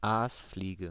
Ääntäminen
IPA: [ˈaːsˌfliːɡə]